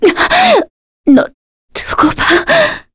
Epis-Vox_Dead_kr.wav